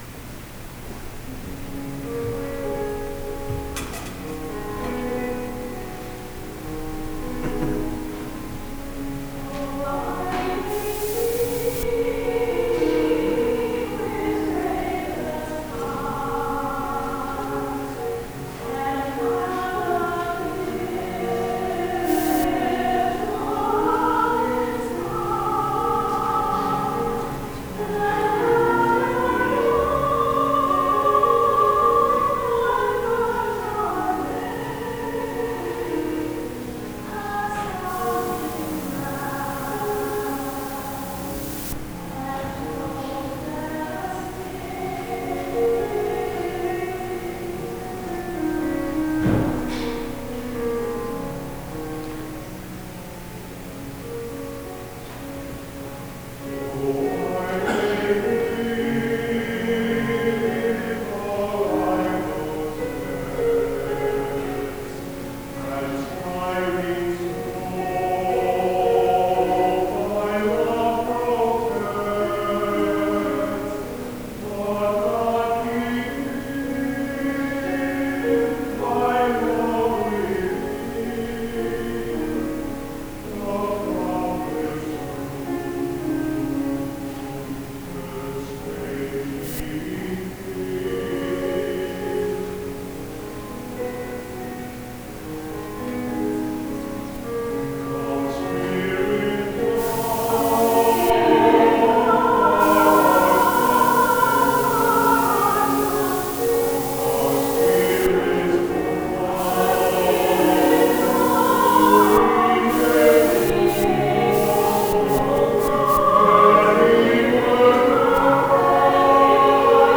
The Adult Choir is a volunteer group of mixed voices which sings at the 10:30 AM Sung Eucharist on Sundays and at special services throughout the liturgical year.
Have a listen to the beauty of our choir!